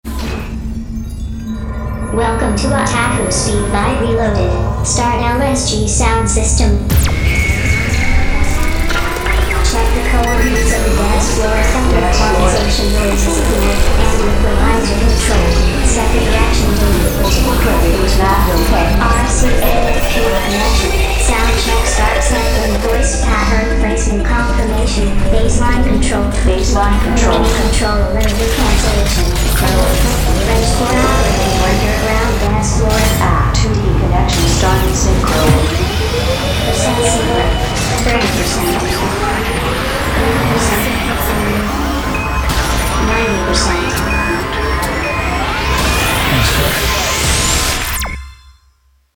J-Core